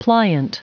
Prononciation du mot pliant en anglais (fichier audio)
Prononciation du mot : pliant